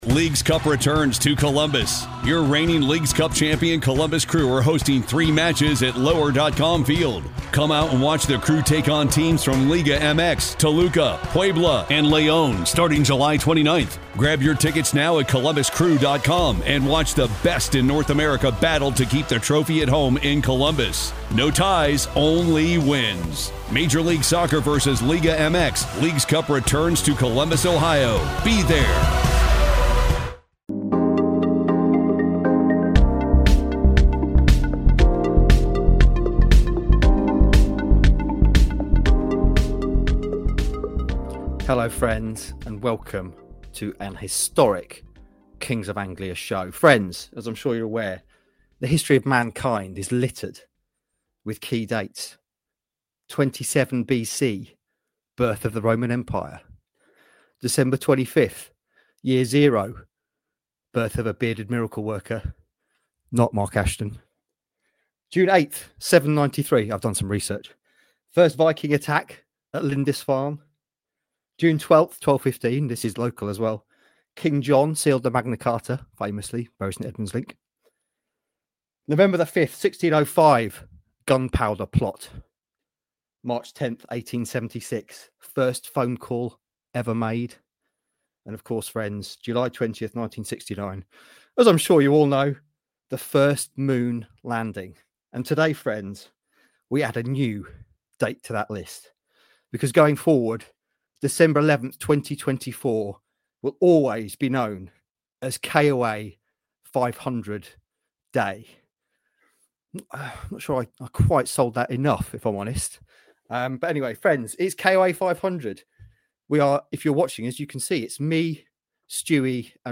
We play highlights from the pods over the years, read emails from the KOA Army and relive some great moments.